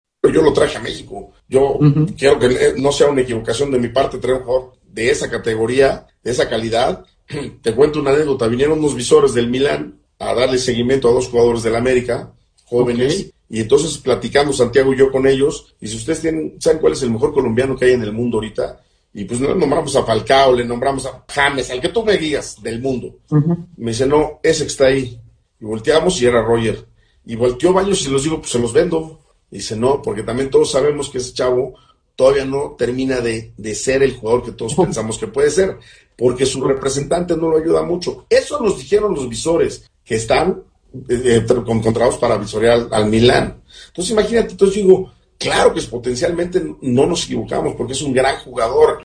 Miguel ‘piojo’ Herrera extécnico del América en charla